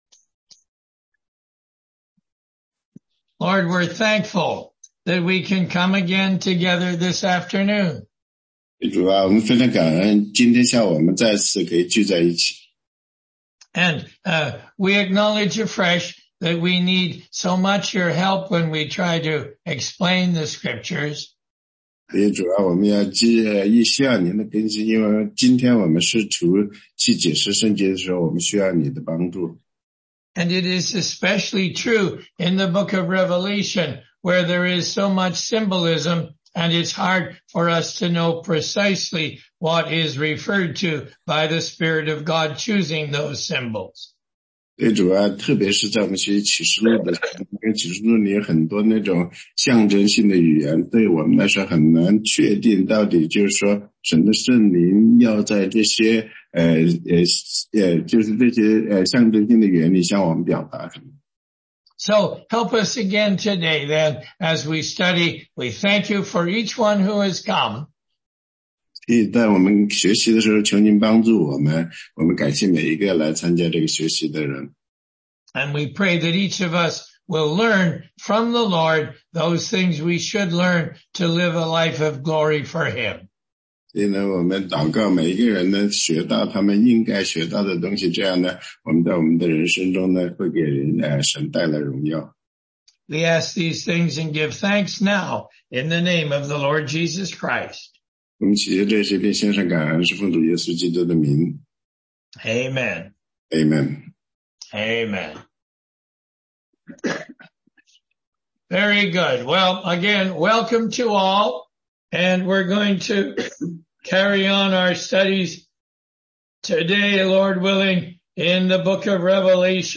16街讲道录音 - 启示录13章
答疑课程